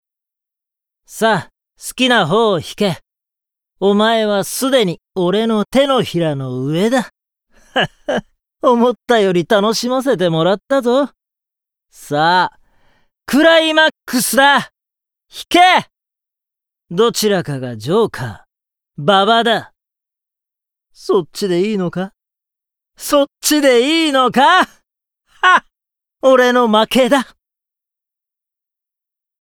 ボイスサンプル ＜ナルシスト＞
5_ナルシスト.mp3